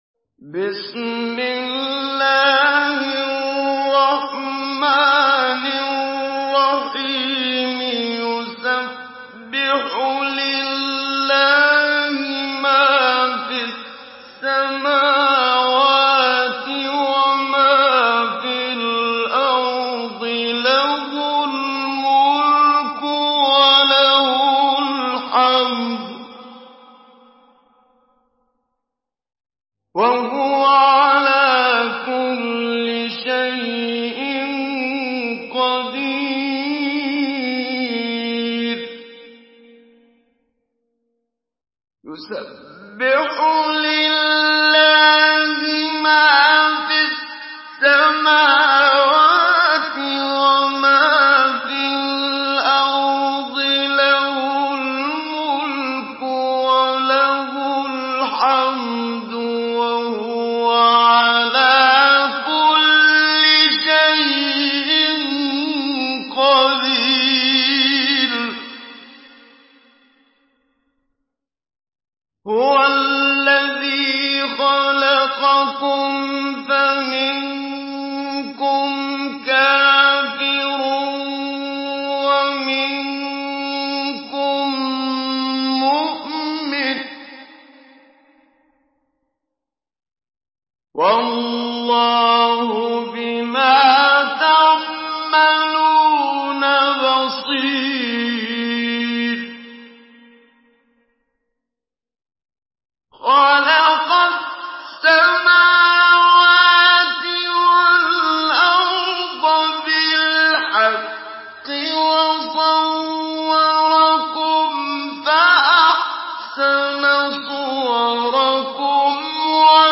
Surah التغابن MP3 in the Voice of محمد صديق المنشاوي مجود in حفص Narration
Surah التغابن MP3 by محمد صديق المنشاوي مجود in حفص عن عاصم narration. Listen and download the full recitation in MP3 format via direct and fast links in multiple qualities to your mobile phone.